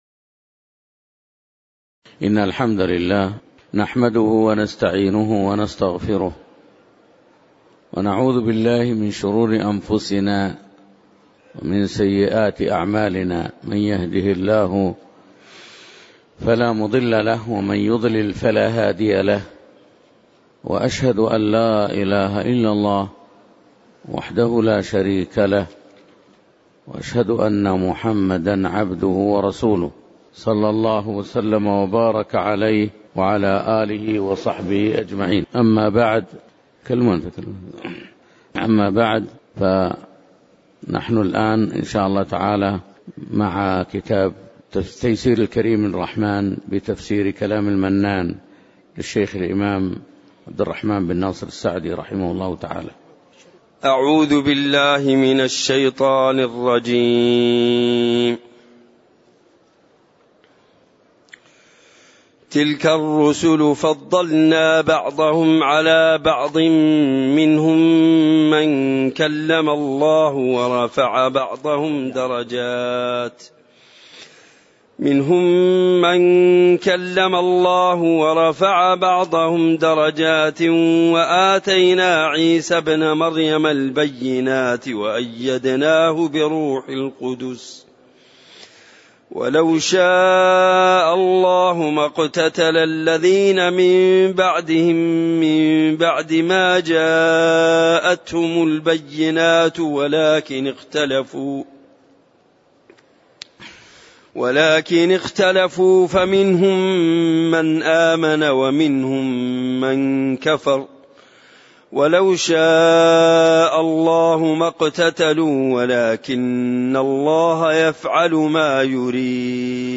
تاريخ النشر ٢٤ جمادى الأولى ١٤٣٩ هـ المكان: المسجد النبوي الشيخ